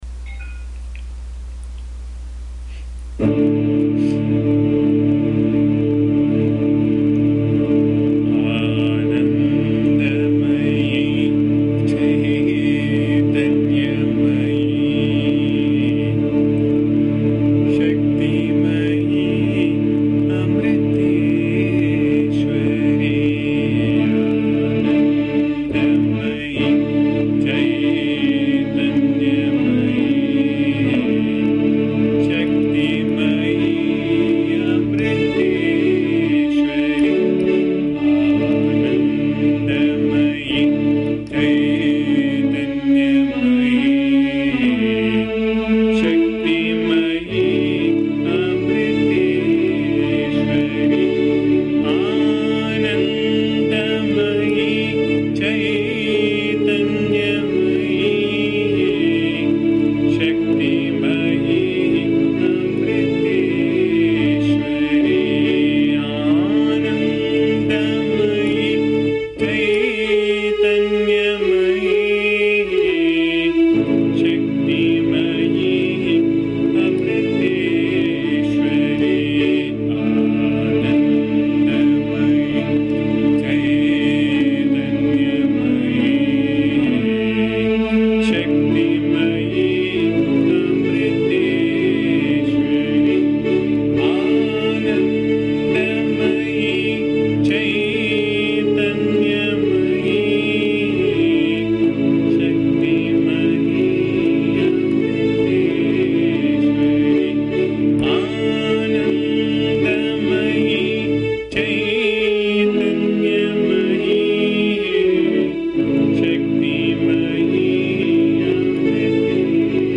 This is a small beautiful bhajan set in Raga Kalyani. It doesn't have much words but induces the divine mother who is of the nature of Consciousness, bliss, immortality and protection.
Please bear the noise, disturbance and awful singing as am not a singer.